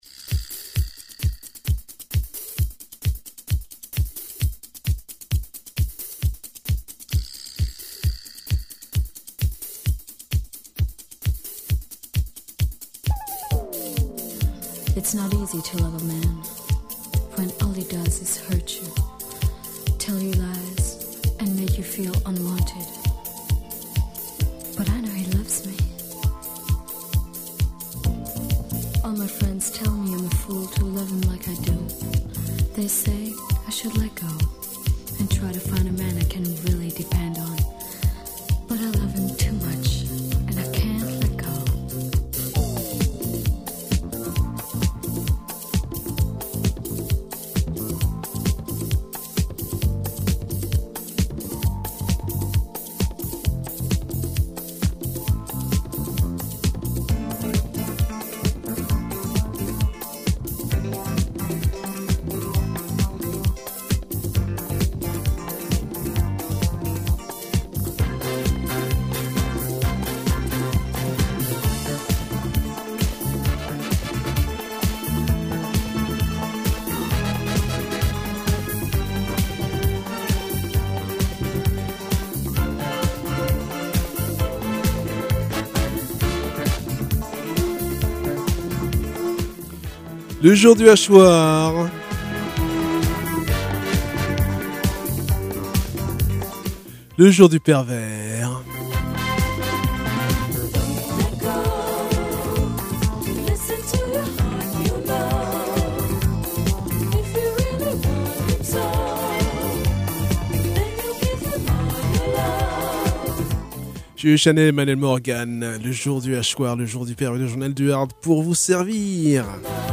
Première partie de l’interview de GBH au Bateau Ivre (16 mai 2024)… Et on termine avec le groupe anarcho punk Death Zone…